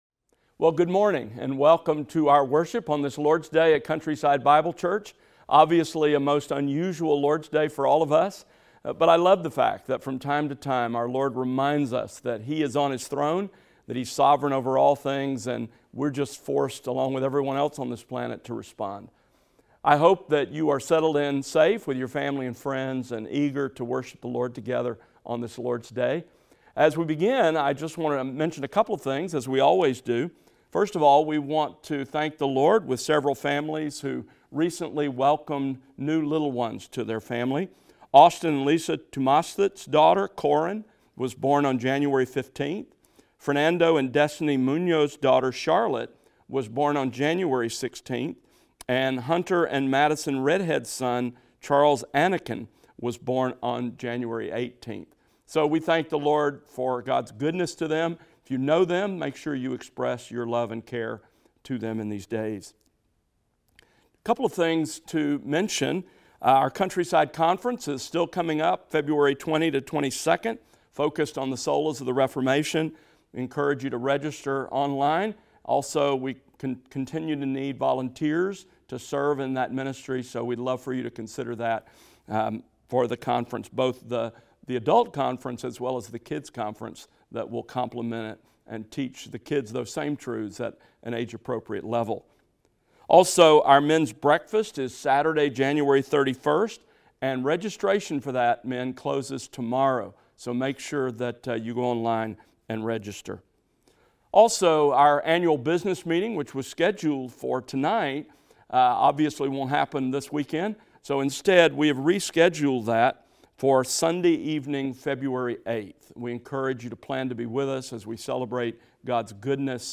Morning Worship Service | Stream Only | Countryside Bible Church
A video service will be streamed on our website and YouTube channel at 9:30 a.m. on Sunday.